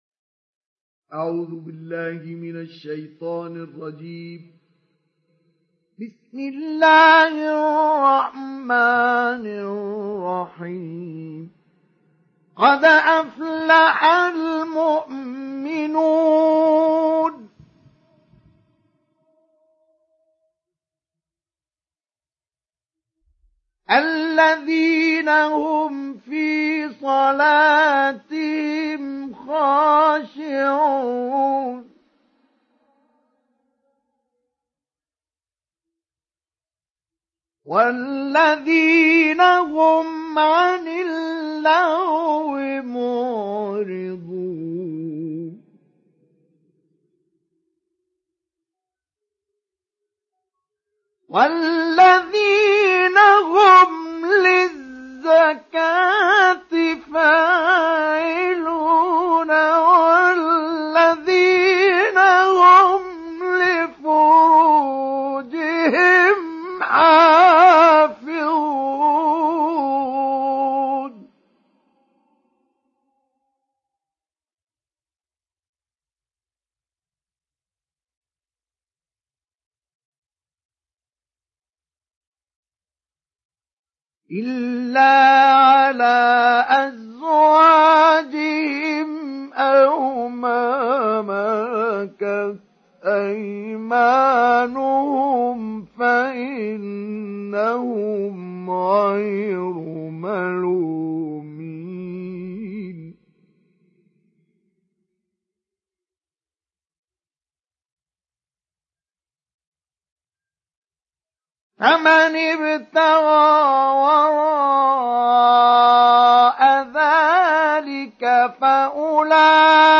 Télécharger Sourate Al Muminun Mustafa Ismail Mujawwad